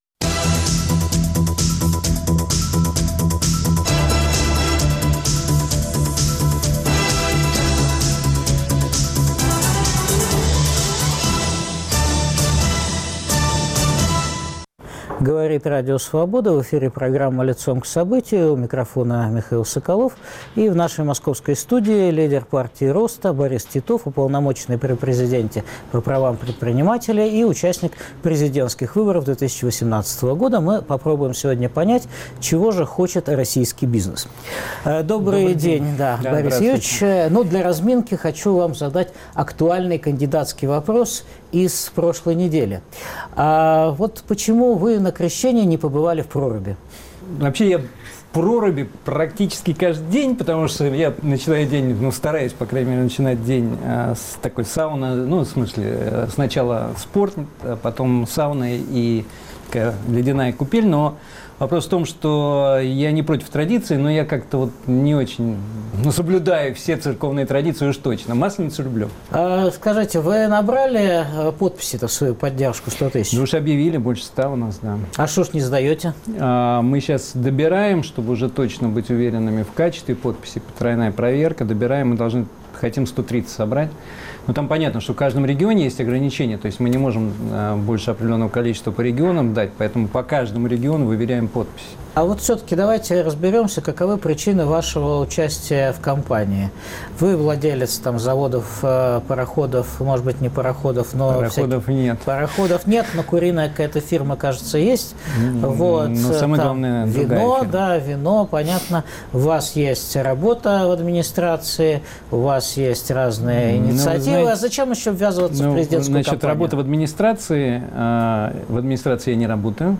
В эфире программы "Лицом к событию" - уполномоченный президента по правам предпринимателей, и лидер Партии роста Борис Титов.